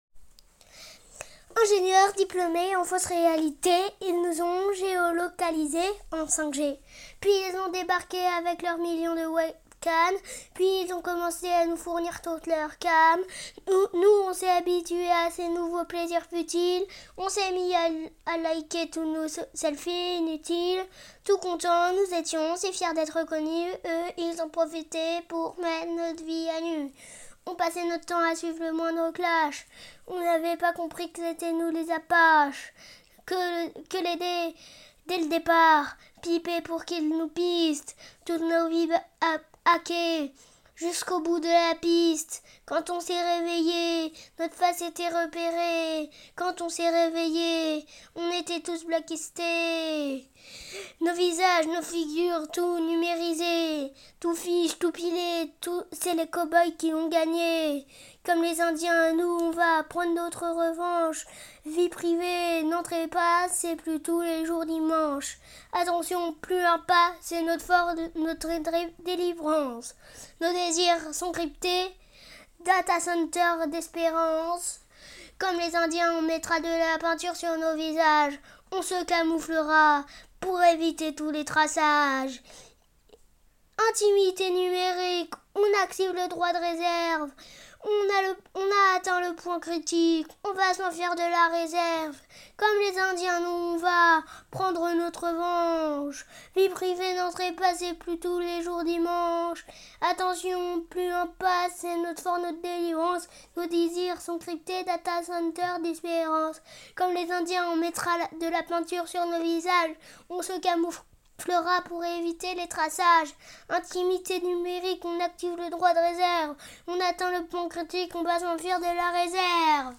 dans Chansons